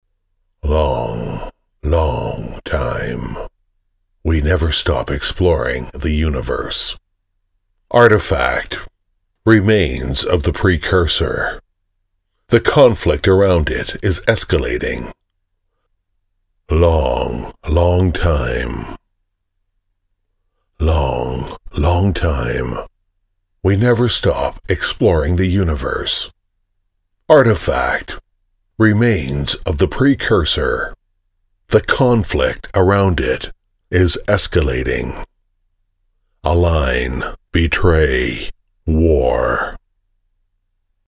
配音风格： 力度 厚重 沉稳 磁性 舒缓